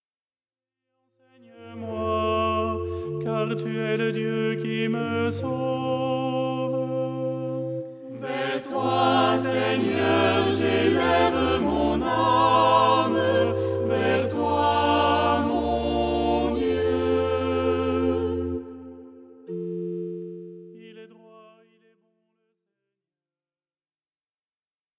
style simple et chantant